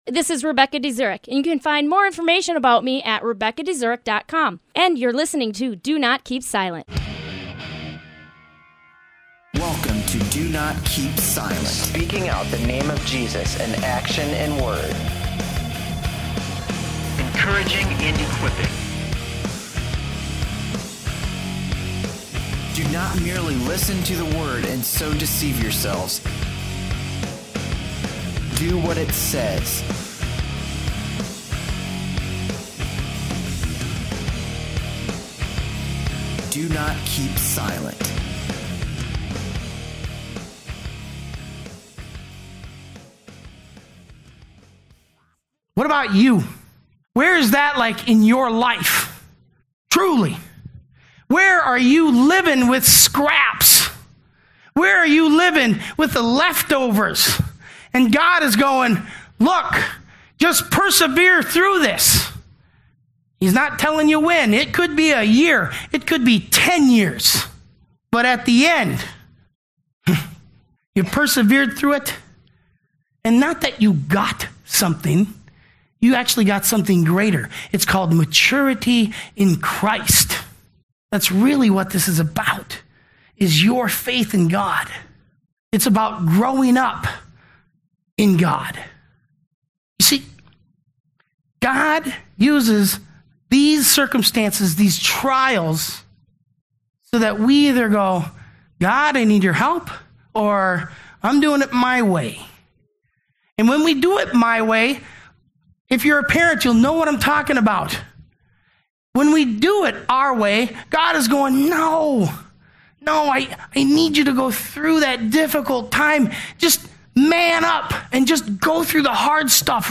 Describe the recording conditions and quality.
- James 1:2-4 In a recent message at The Catalyst Church